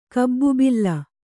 ♪ kabbubilla